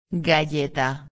29/11/2011 Galleta Bolacha •\ [ga·lle·ta] \• •\ Substantivo \• •\ Feminino \• Significado: Espécie de biscoito de farinha muito fina.
galleta.mp3